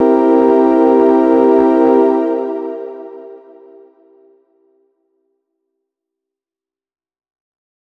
005_LOFI CHORDS DOM7_3.wav